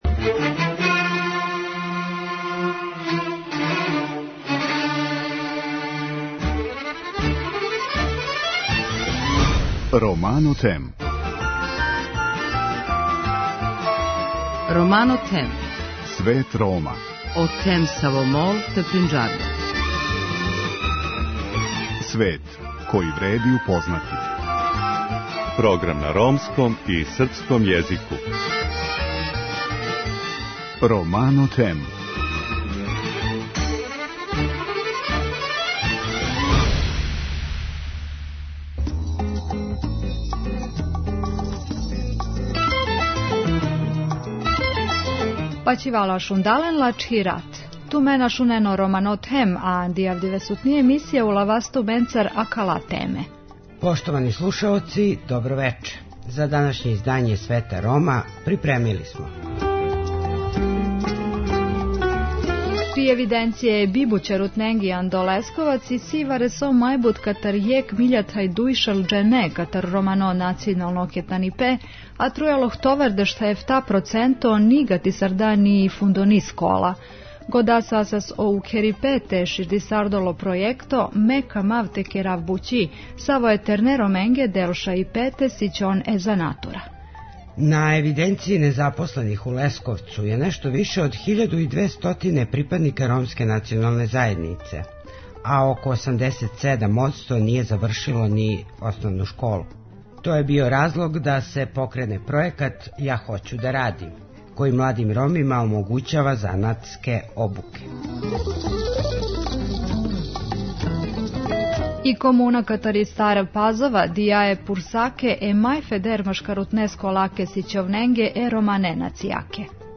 Тим поводом гост емисије је Ненад Иванишевић, председник Координационог тела за праћење реализације Стратегије за социјално укључивање Рома и Ромкиња.